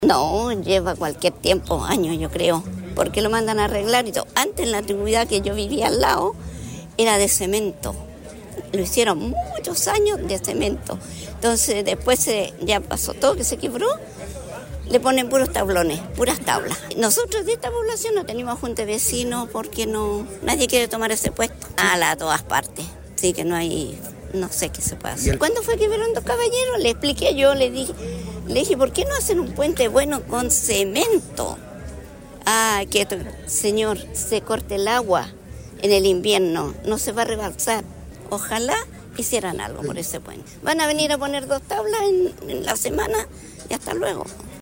Vecina de Codigua reclama por mal estado de puente y pide solución para evitar alguna tragedia